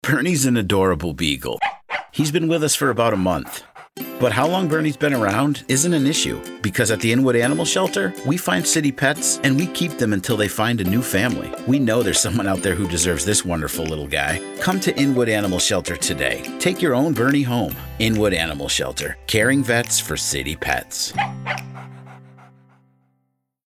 A Professional American Male Voice Actor With A Smooth & Vibrant Delivery
Authentic Male Voiced Ad For Animal Shelter